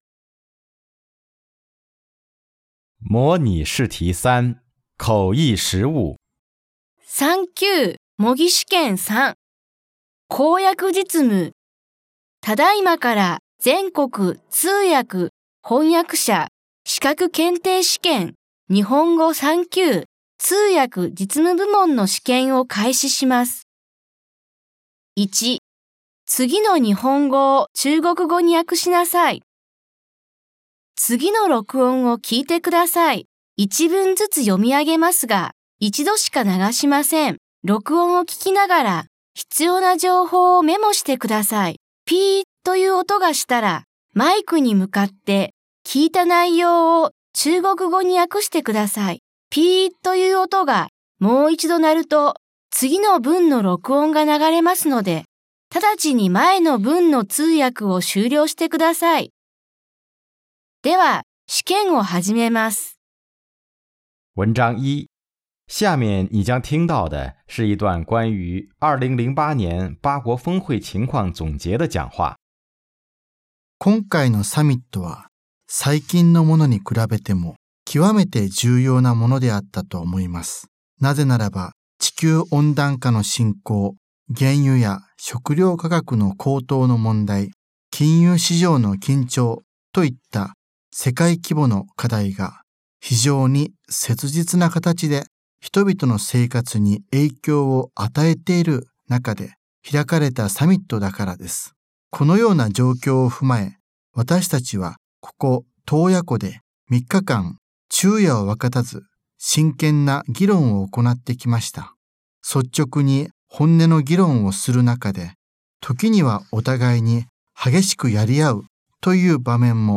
语音下载 [听力原文] 下面你将听到的是一段应对气候变化的国际合作的演讲。